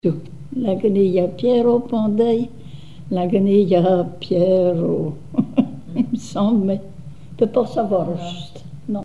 Couplets à danser
branle : avant-deux
Chansons et témoignages
Pièce musicale inédite